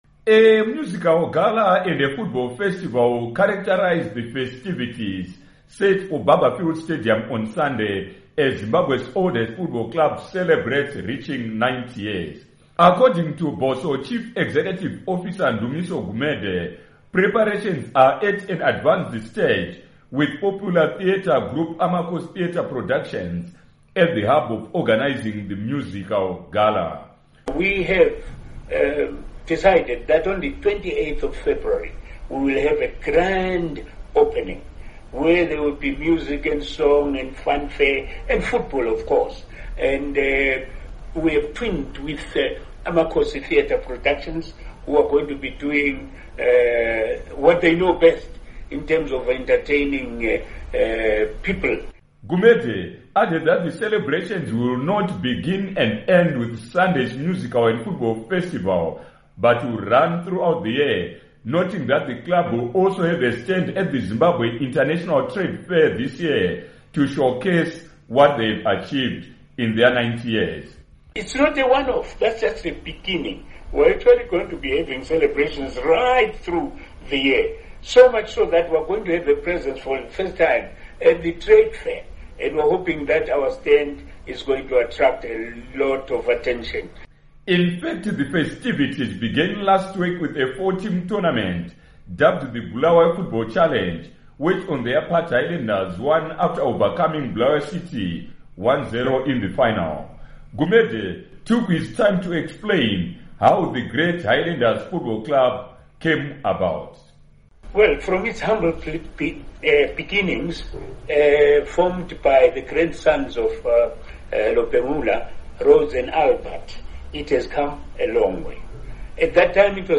Report on Highlanders FC 92nd Anniversary Celebrations